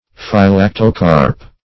Search Result for " phylactocarp" : The Collaborative International Dictionary of English v.0.48: Phylactocarp \Phy*lac"to*carp\, n. [Gr. fyla`ssein to guard + karpo`s fruit.]